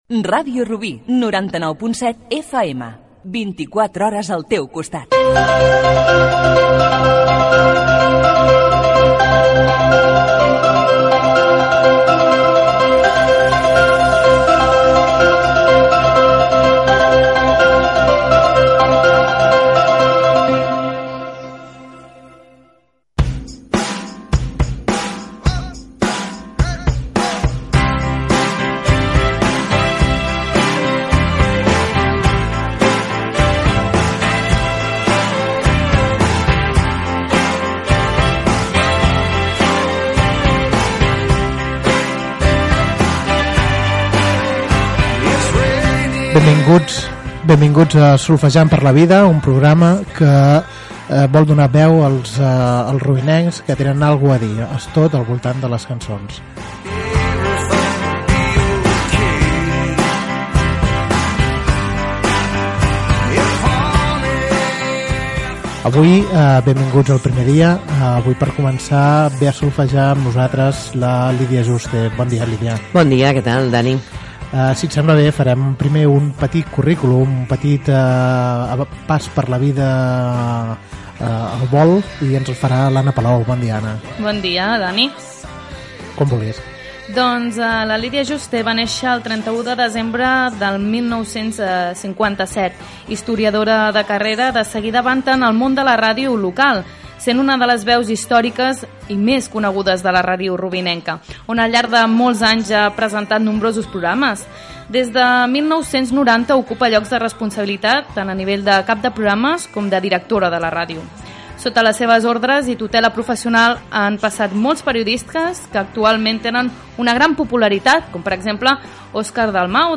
Indicatiu de l'emissora, sintonia, presentació, biografia i entrevista